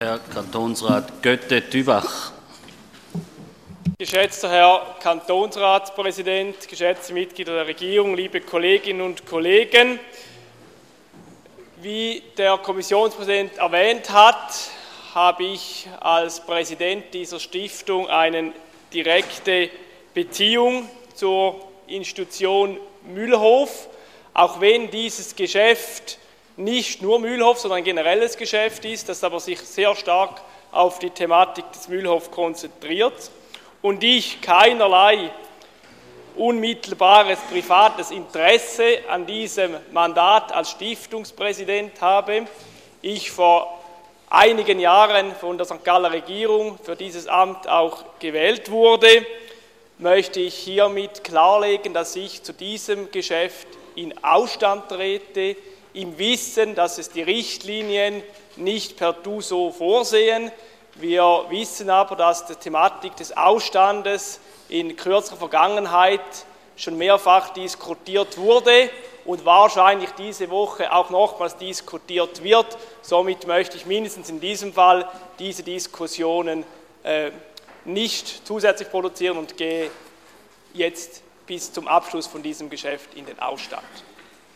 Session des Kantonsrates vom 24. und 25. Februar 2014